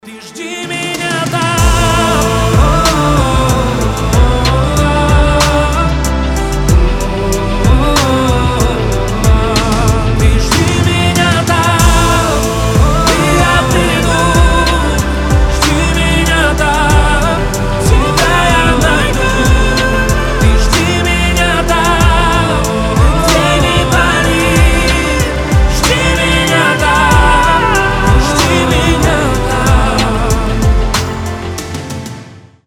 • Качество: 320, Stereo
красивый мужской голос
спокойные
Замечательная романтичная баллада